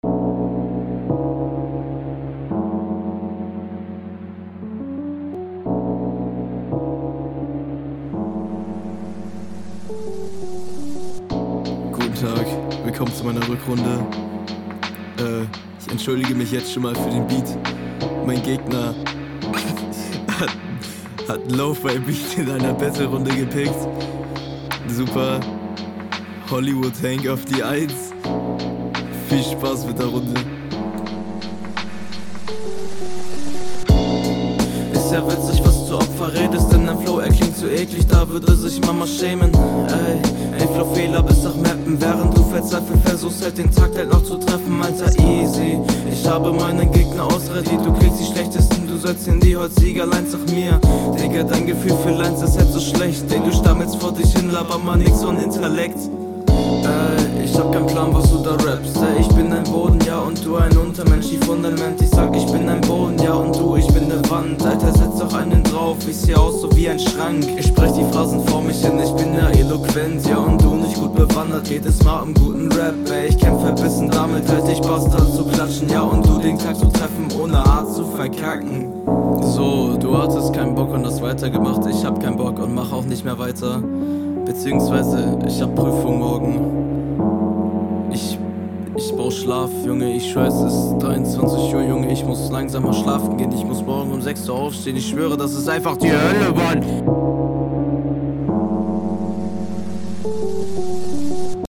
Nimm das Ding mal nicht so ernst hier ... und sprich deine Silben korrekt aus, …